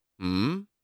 Hmmm Sound.wav